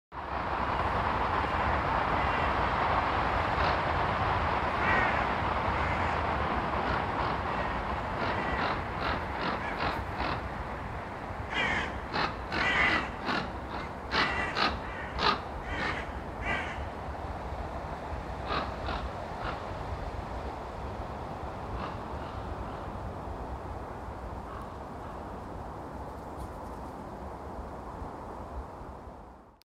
managed to record a bit of it on my phone - not a great recording but clear enough.